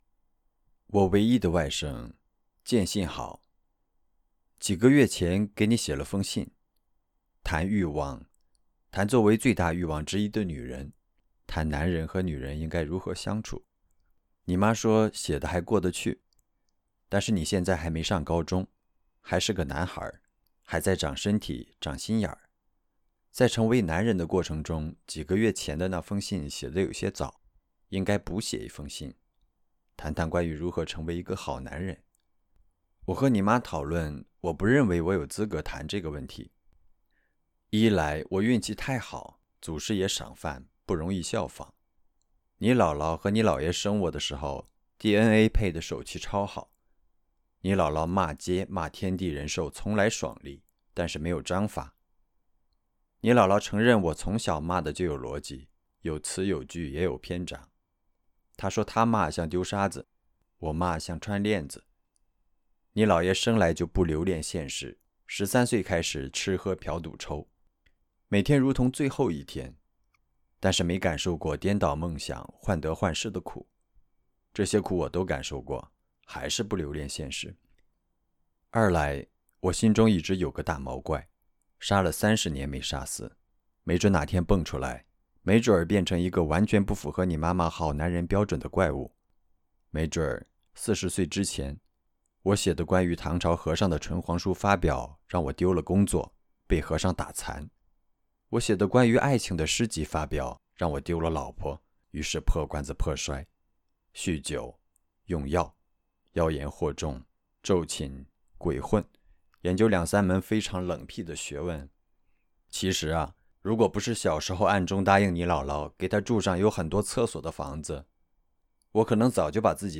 冯唐写给外甥的一封信 原声音频